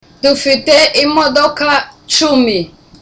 (Loudly)